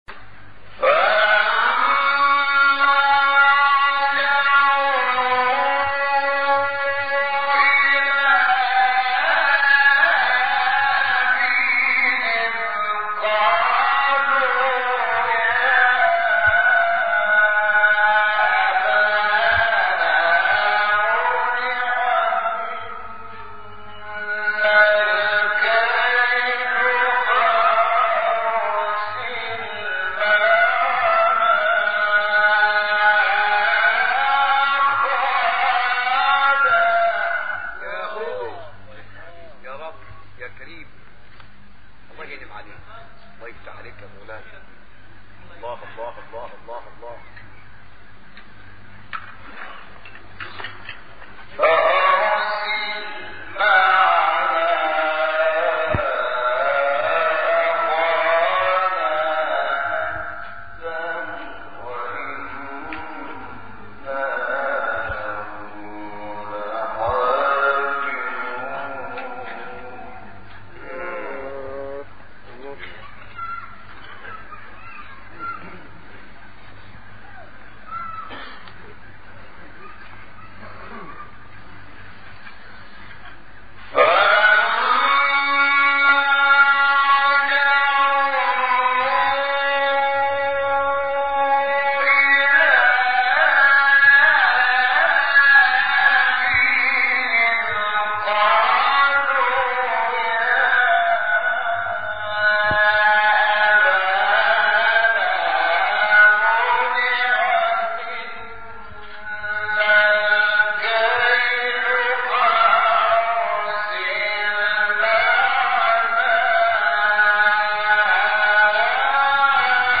سوره : یوسف آیه: 63-65 استاد : شحات محمد انور مقام : بیات قبلی بعدی